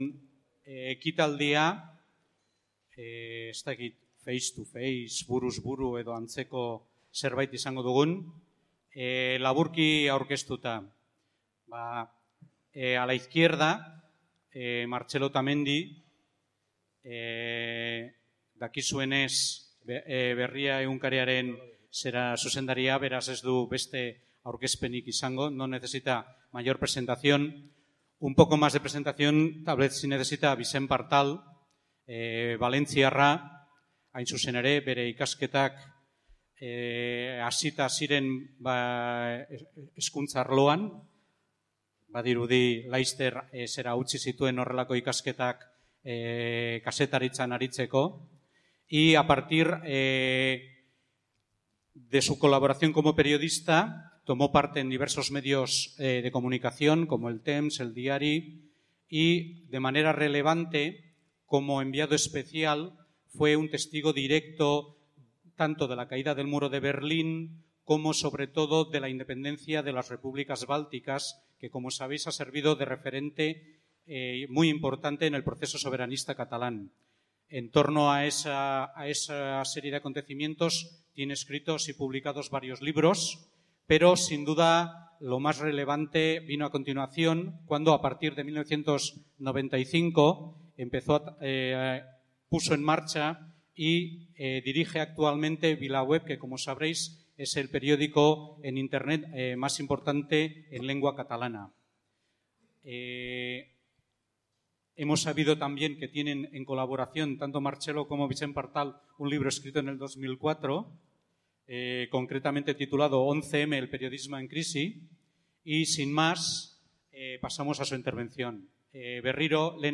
solasean entzuteko aukera izan genuen Kafe Antzokian, Katalunian irailaren 27an egingo diren hauteskunde plebiszitarioek irekiko duten jokaleku politiko berria aztertu zuten jendaurrean.